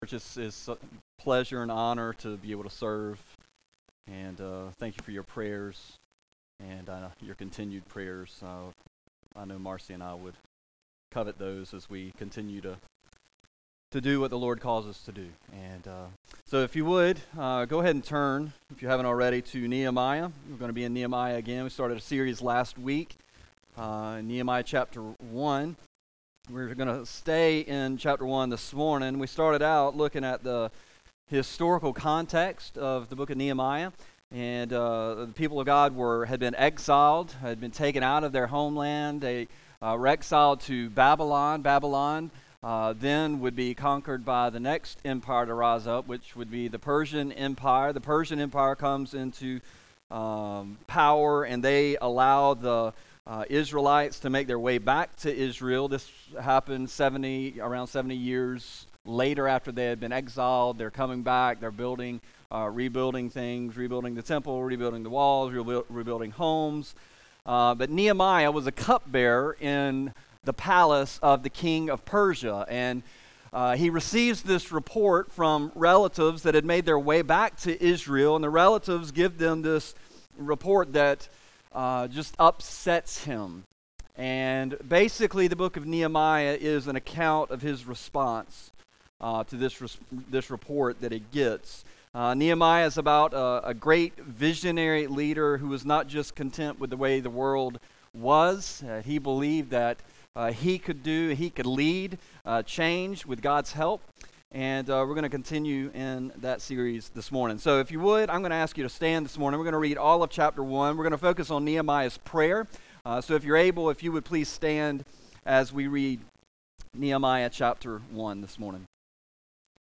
A message from the series "Nehemiah."